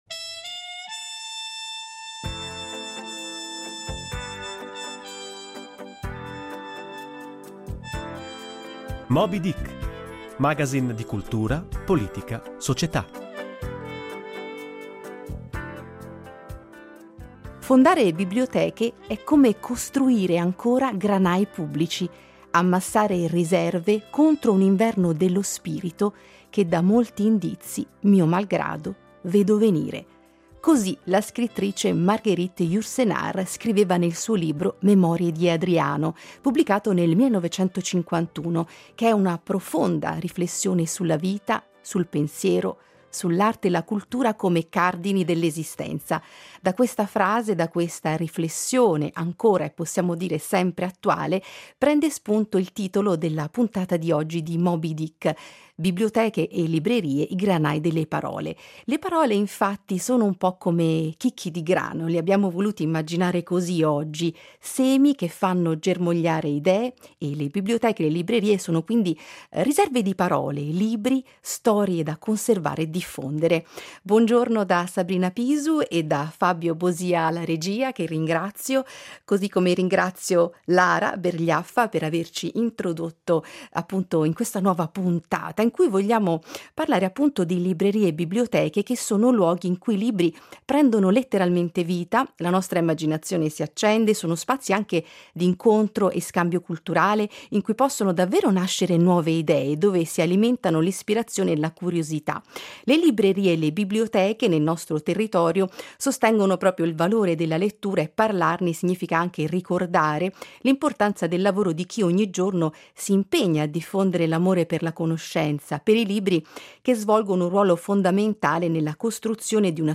Moby Dick è il magazine del sabato mattina che mira a mettere a confronto, attorno ai grandi temi di cultura, politica, società, economia, ospiti di sensibilità e opinioni diverse, anche radicalmente contrapposte. Coglie momenti e tematiche di particolare rilievo e le pone al centro di una tavola rotonda per scandagliarne peculiarità e sfumature.